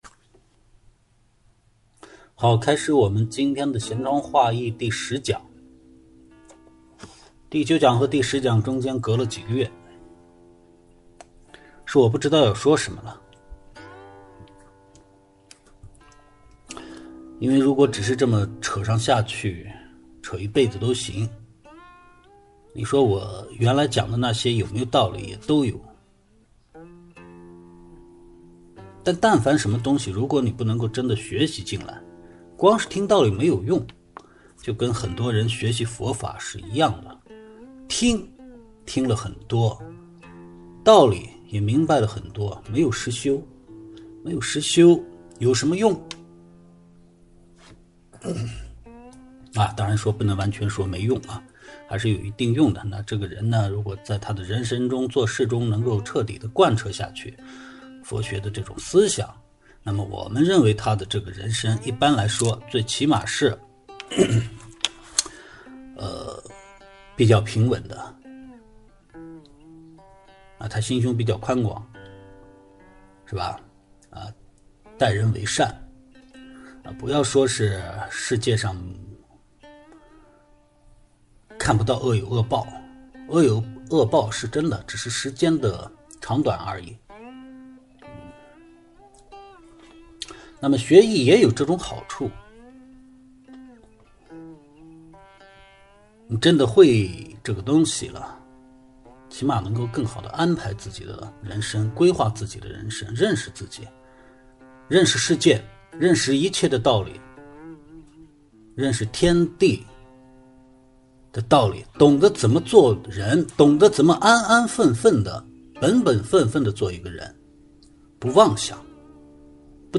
闲窗话易第十讲带背景版.mp3